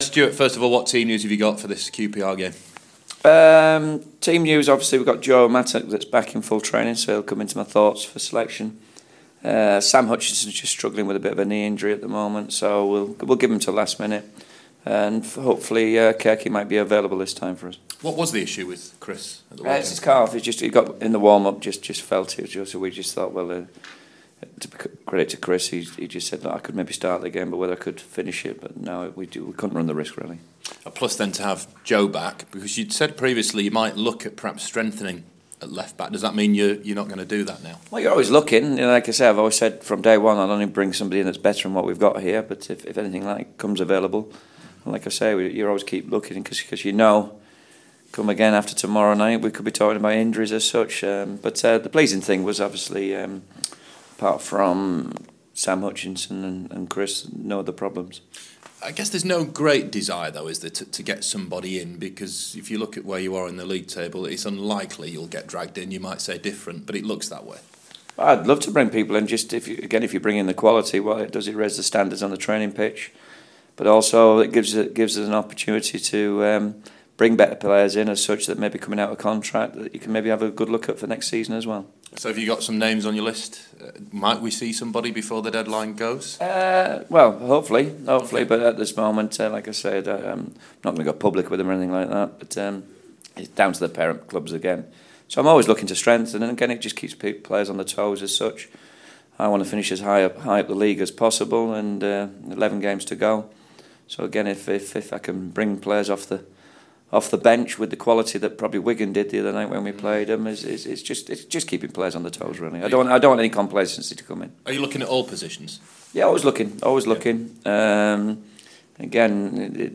Sheffield Wednesday head coach Stuart Gray previews QPR test